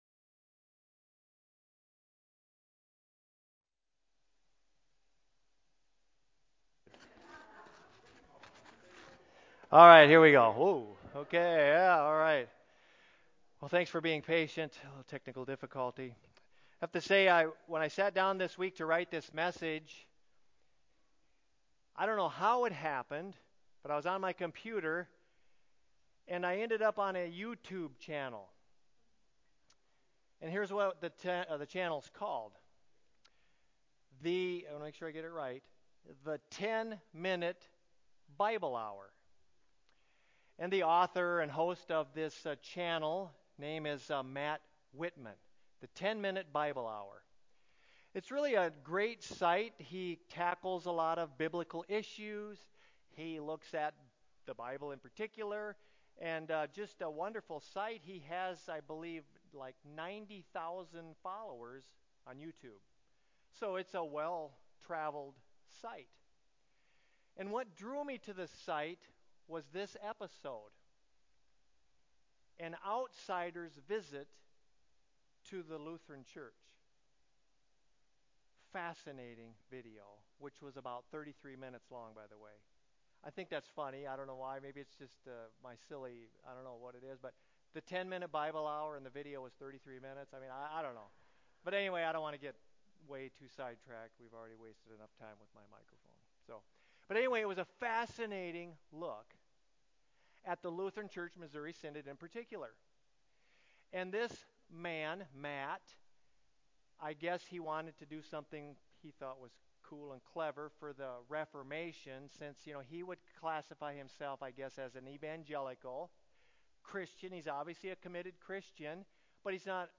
church-sermon11.3.19-CD.mp3